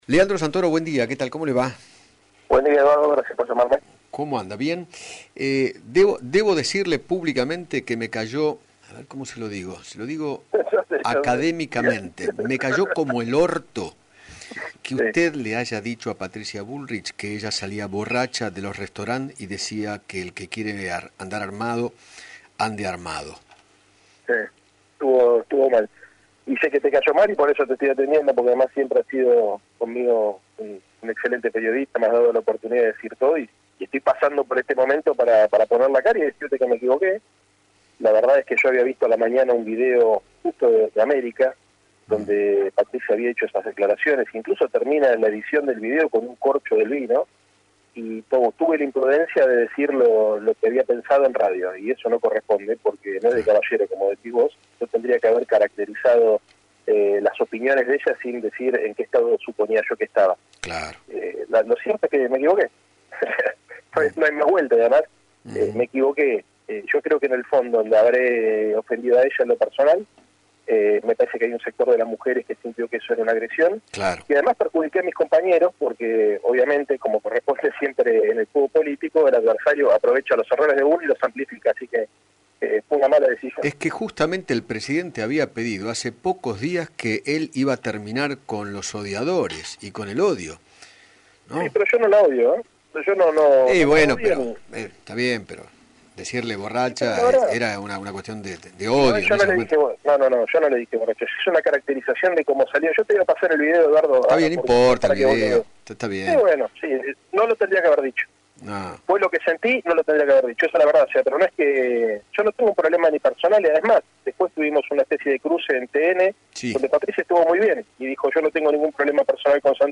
Leandro Santoro, legislador porteño, dialogó con Eduardo Feinmann sobre sus dichos contra la ex Ministra de Seguridad, a quien trató de borracha. Además, habló acerca de las relaciones entre los que componen su coalición política.